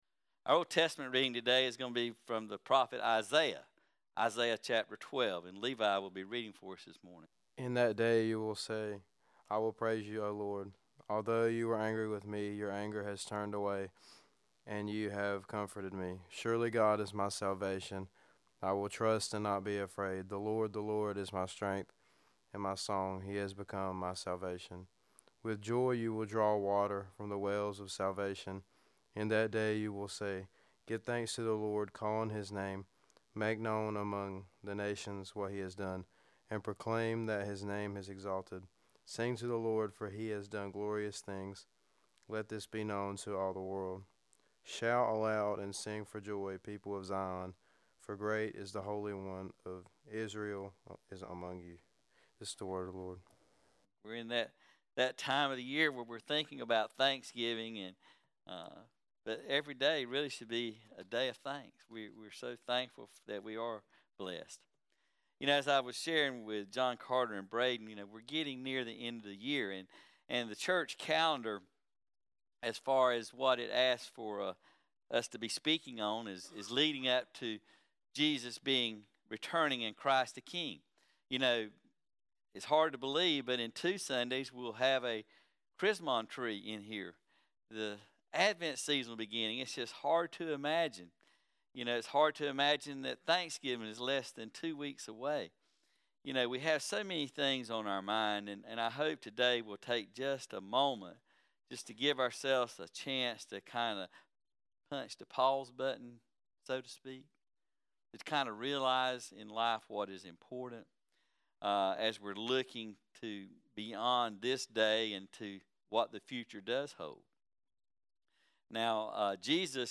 5-19 Sermon: Facing the Future